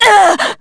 Lucikiel_L-Vox_Damage_jp_03.wav